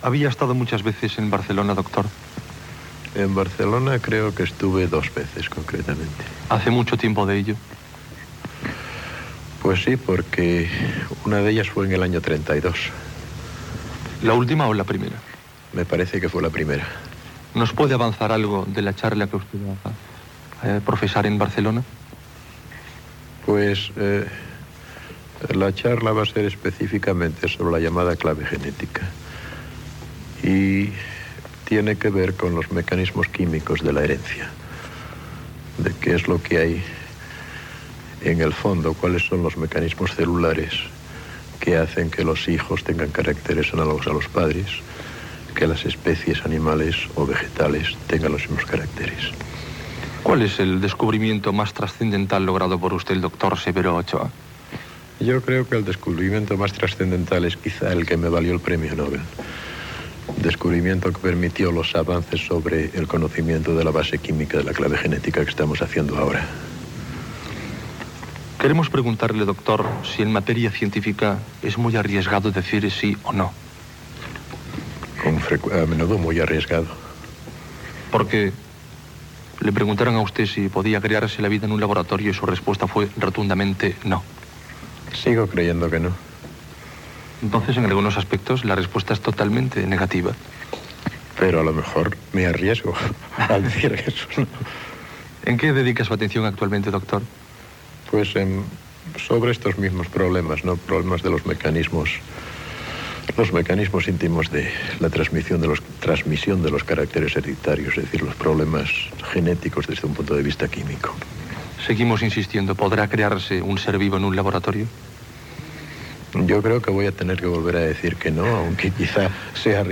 Entrevista al Doctor Severo Ochoa sobre una xerrda sobre genètica que farà a Barcelona, el premi Nobel que va rebre i la seva vida als EE.UU.
Informatiu